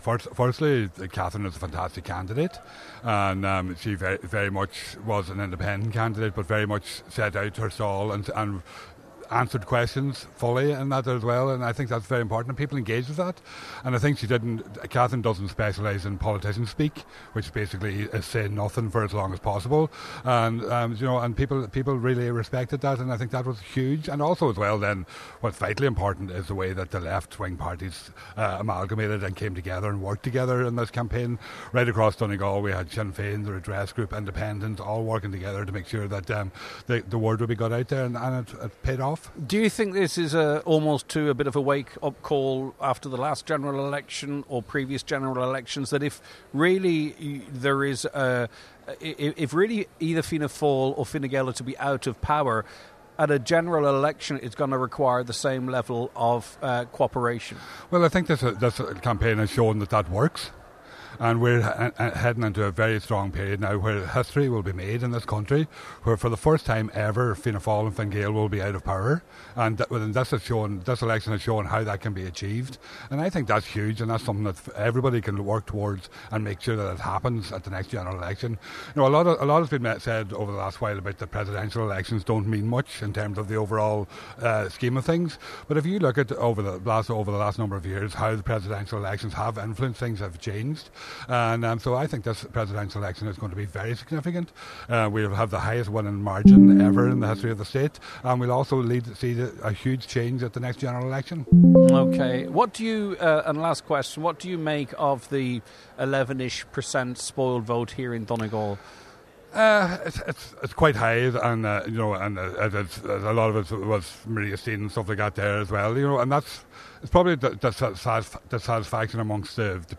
Thomas Pringle, who was part of the candidate’s campaign in Donegal, was speaking at the count in the Aura Leisure Centre in Letterkenny this afternoon.